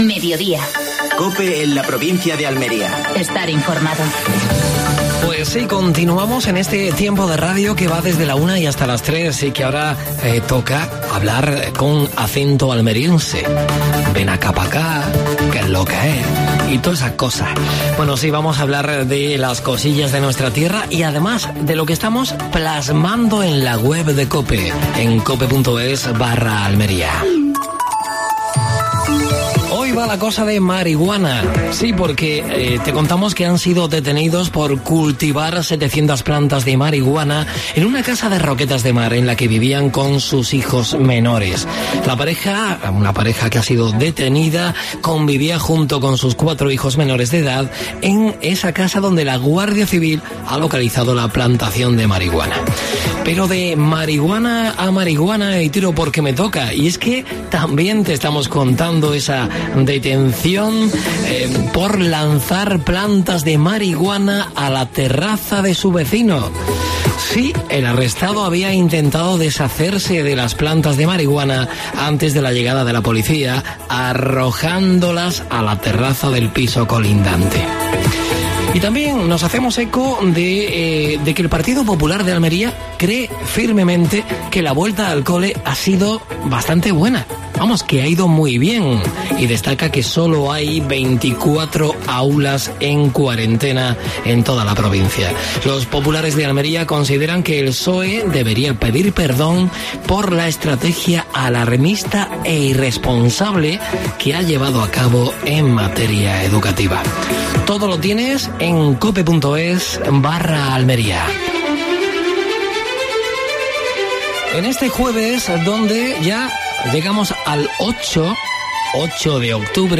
AUDIO: Actualidad en Almería. Entrevista